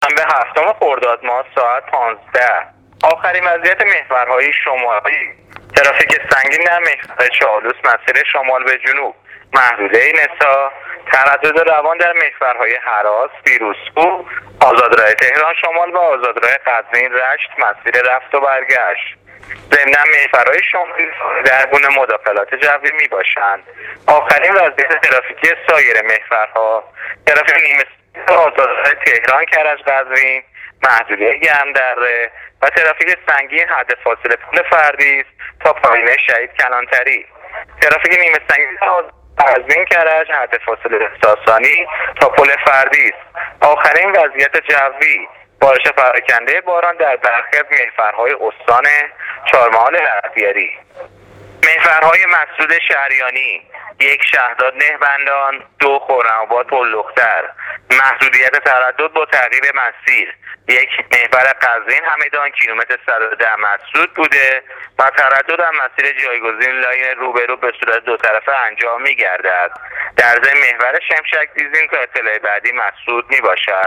گزارش رادیو اینترنتی از وضعیت ترافیکی جاده‌ها تا ساعت ۱۵ هفتم خردادماه